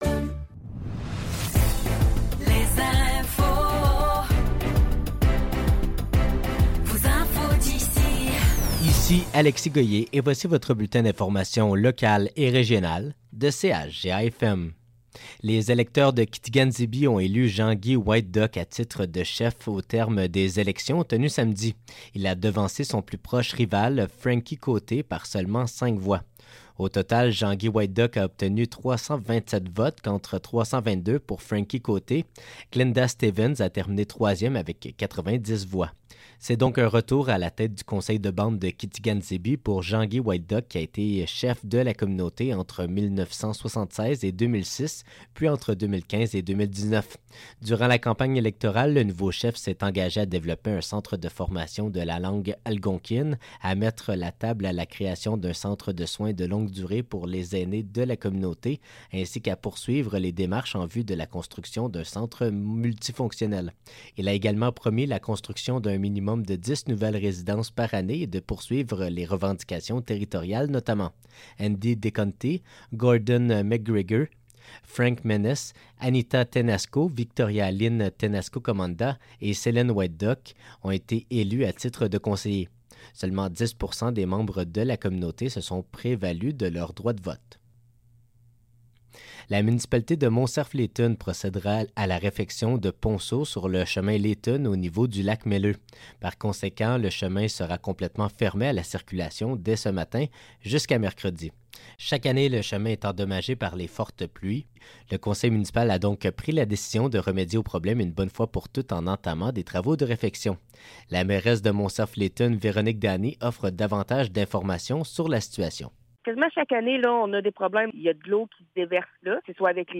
Nouvelles locales - 26 Août 2024 - 12 h